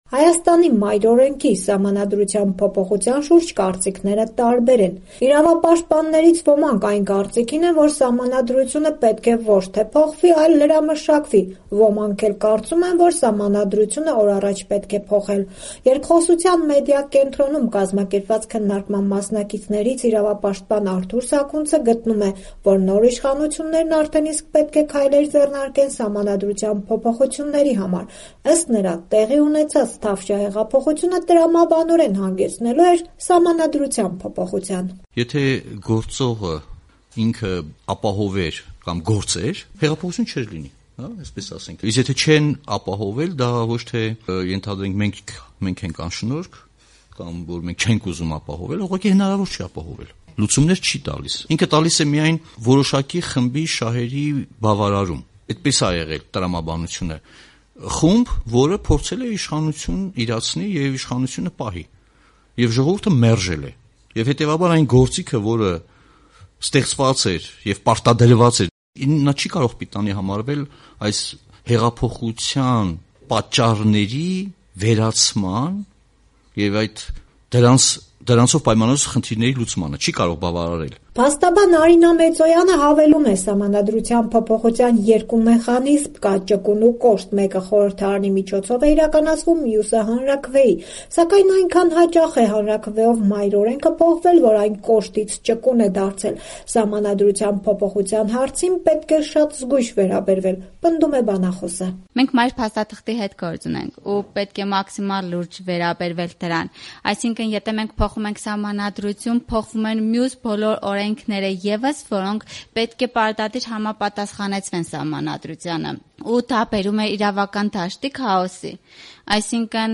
Կասկած չկա, որ ներկայիս Սահմանադրությունը պետք է փոփոխել․ քննարկում Գյումրիում